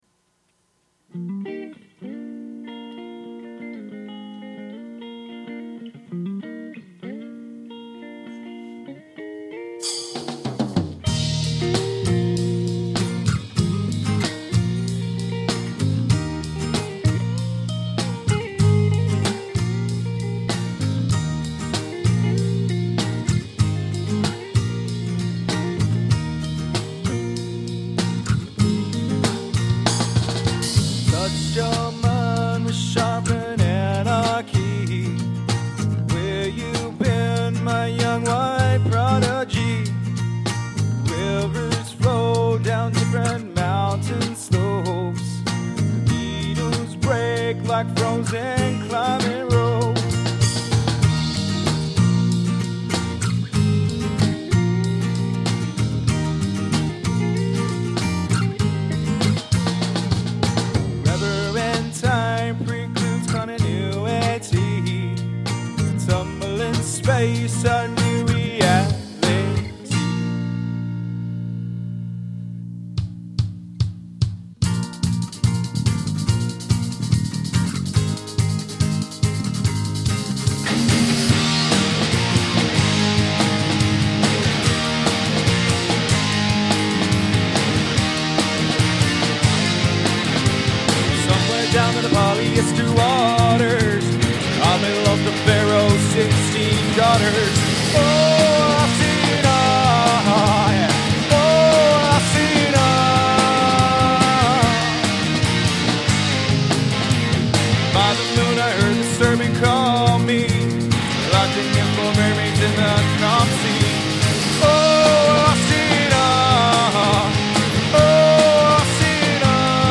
rough mix